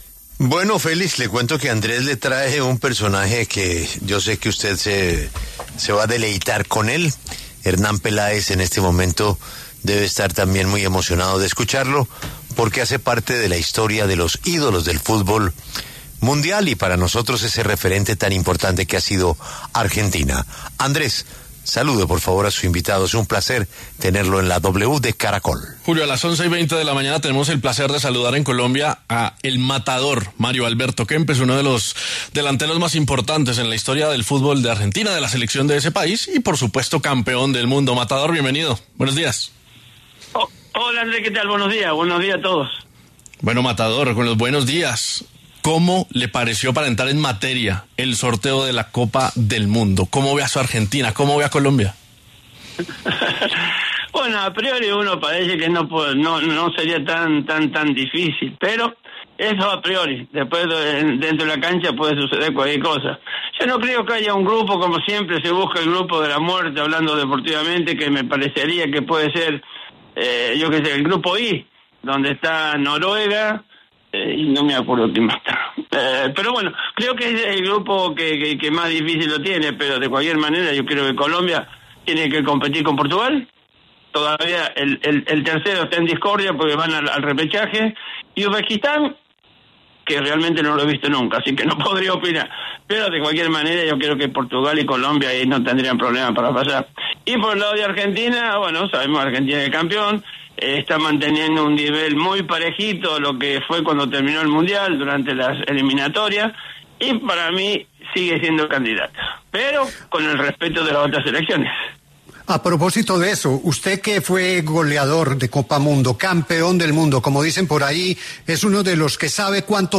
‘El Matador’ Kempes habló en La W y dijo cuáles son sus selecciones candidatas a quedarse con la Copa del Mundo 2026.